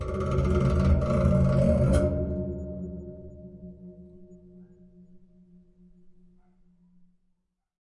电风扇金属烤架采样 " 电风扇烤架 刮痧 2
描述：电风扇作为打击乐器。击打和刮擦电风扇的金属格栅可以发出美妙的声音。
Tag: 金属的 混响 电动风扇 样品